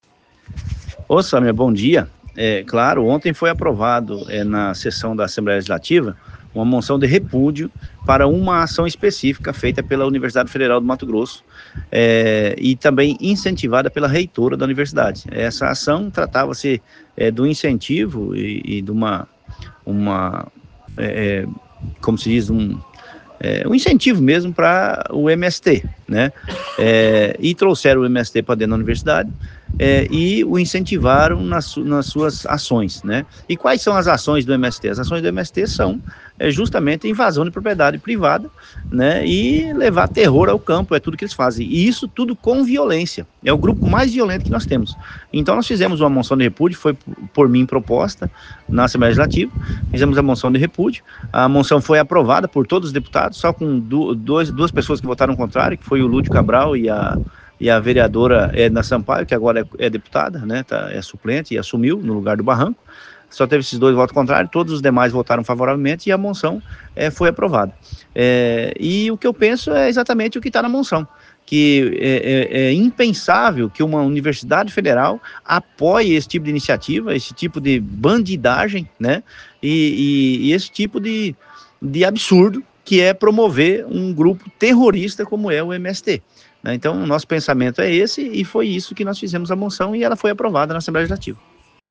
ESCUTE OS ÁUDIOS DA ENTREVISTA DADA PELO DEPUTADO GILBERTO CATTANI AO OPINIÃO MT